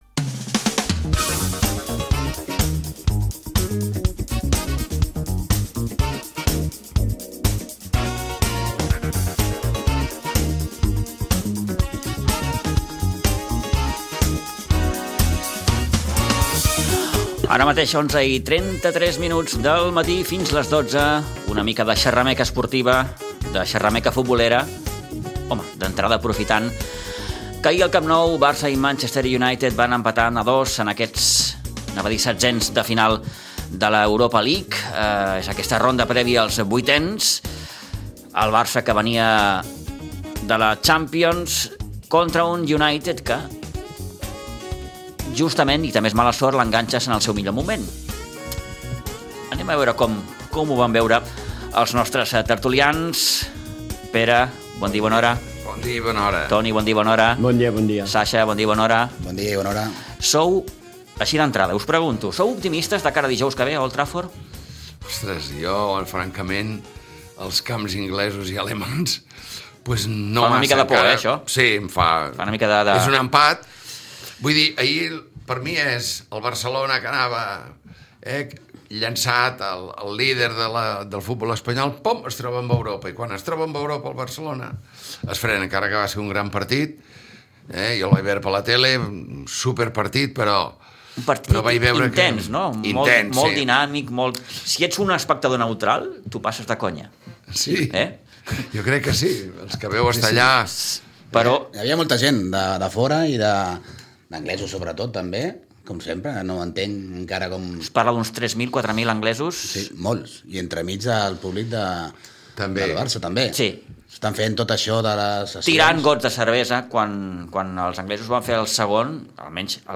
La tertúlia esportiva dels divendres